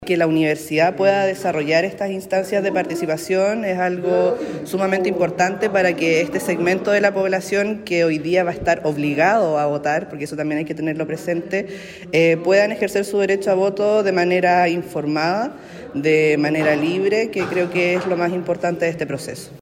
El área Social-Comunitaria del Departamento de Psicología de la Facultad de Ciencias Sociales UdeC organizó el conversatorio «En pos de un voto informado», que contó con la participación de los ex integrantes de la Convención Constitucional, Fernando Atria y Paulina Veloso.